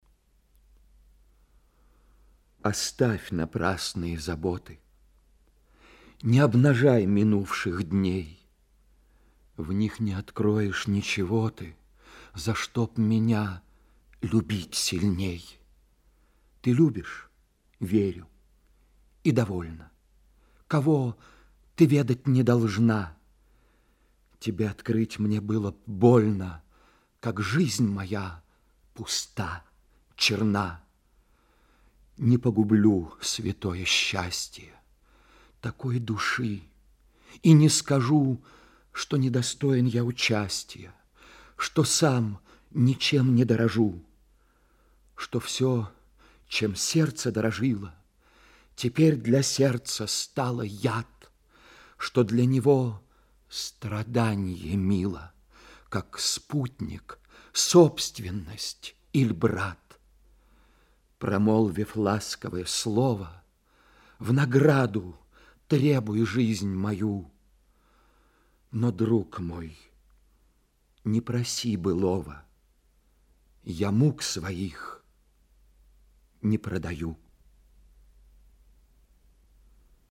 2. «М.Ю. Лермонтов “Оставь напрасные заботы…&qu – (читает Геннадий Бортников)» /
Yu.-Lermontov-Ostav-naprasnye-zaboty.qu-chitaet-Gennadij-Bortnikov-stih-club-ru.mp3